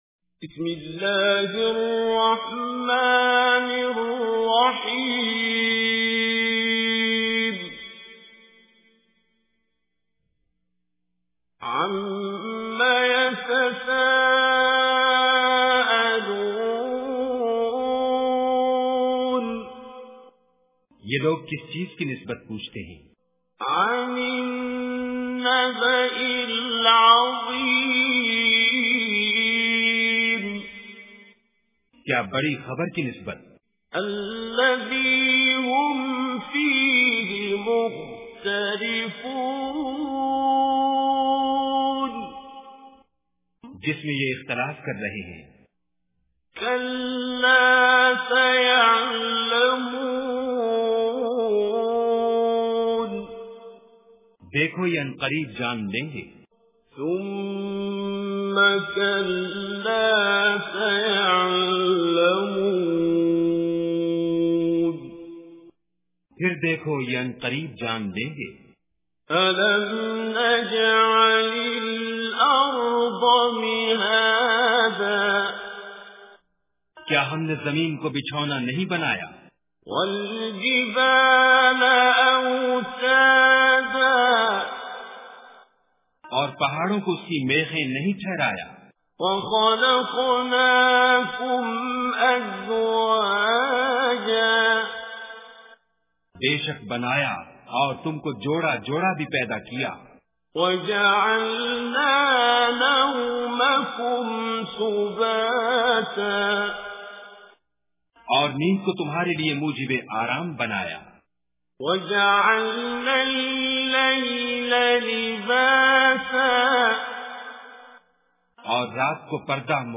Surah An-Naba is 78 chapter or Surah of Holy Quran. Listen online and download mp3 tilawat / Recitation of Surah An-Naba in the voice of Qari Abdul Basit As Samad.